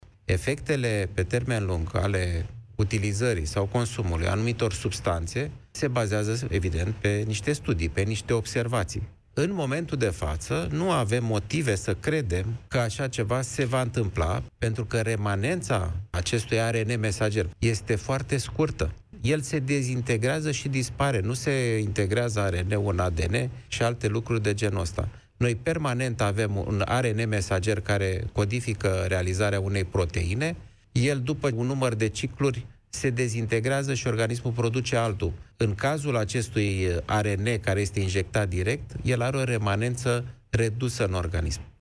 Invitat la emisiunea Piața Victoriei, la Europa FM, medicul Alexandru Rafila, a comentat și situația în care școlile ar fi trebuit să fie închise la o rată a incidenței de 6 îmbolnăviri la mia de locuitori.